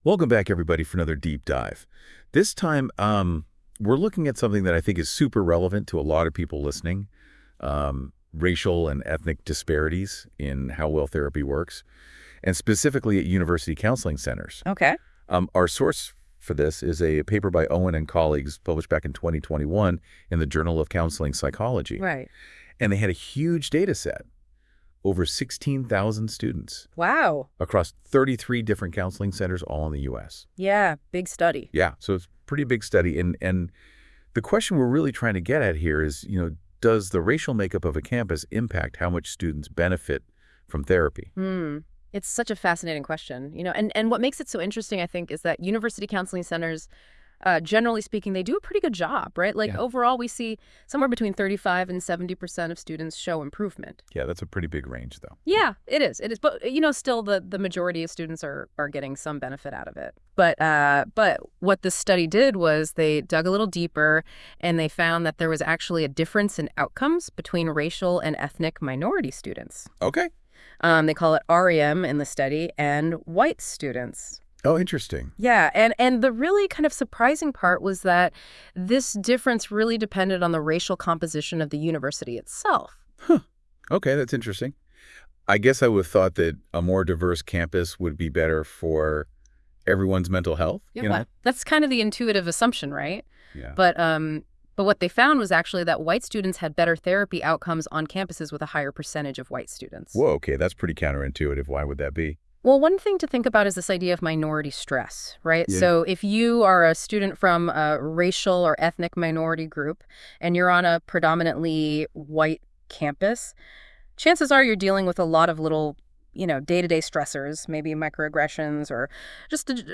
This podcast was generated by Notebook LM and reviewed by our team, please listen with discretion.